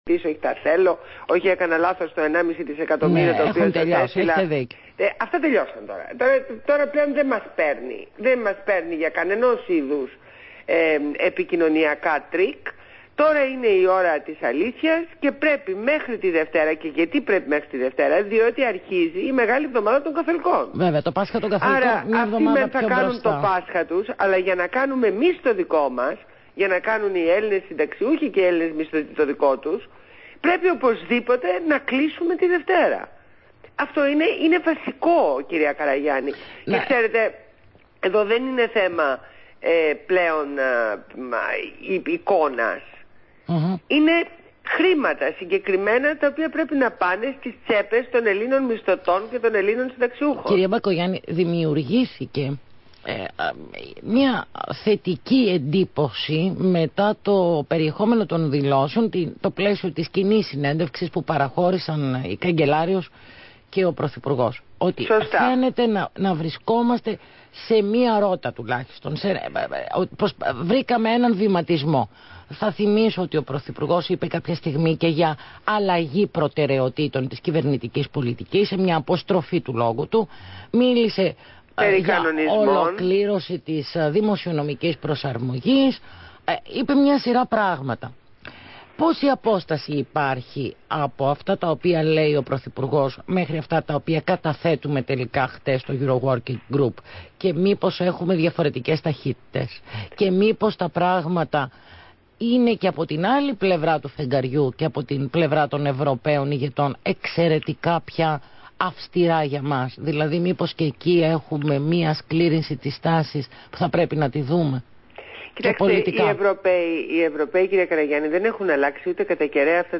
Συνέντευξη στο ραδιόφωνο Αθήνα 9,84